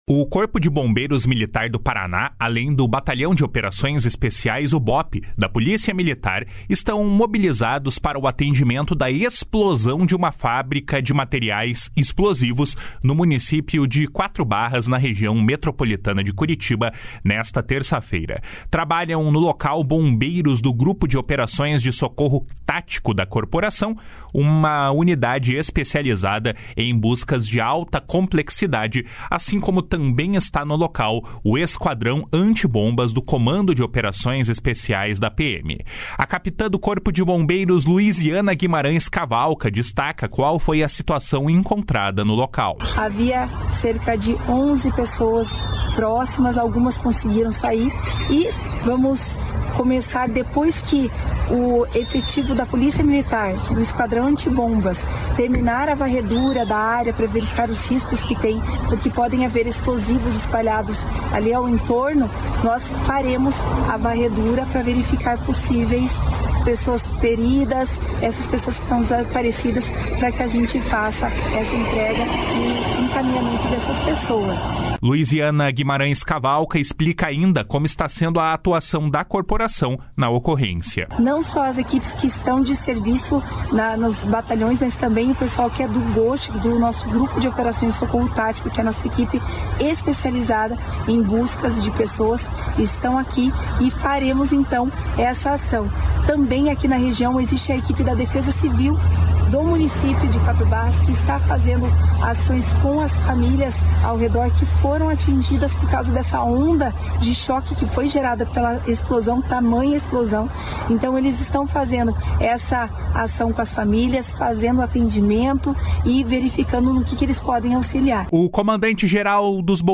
O comandante-geral dos Bombeiros, coronel Antonio Geraldo Hiller, falou mais sobre a área afetada pelo incidente.